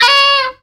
SQWAUL.wav